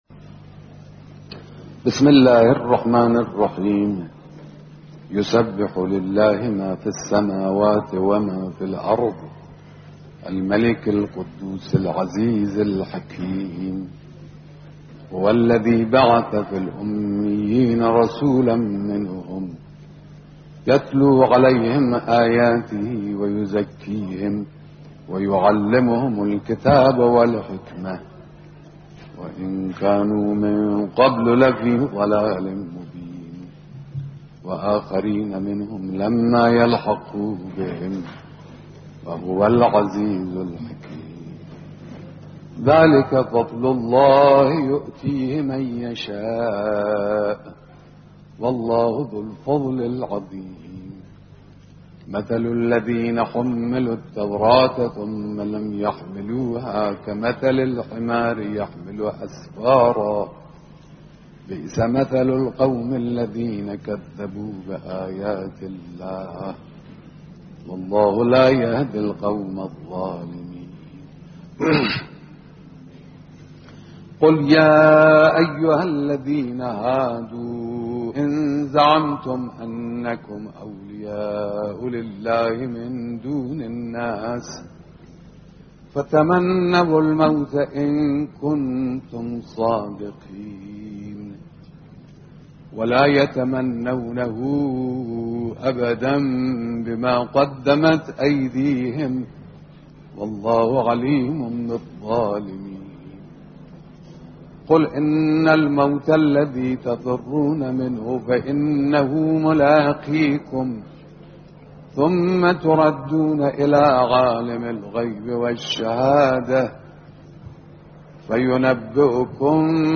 قرائت سوره جمعه با صدای رهبر انقلاب
قرائت قرآن با صدای آیت الله خامنه‌ای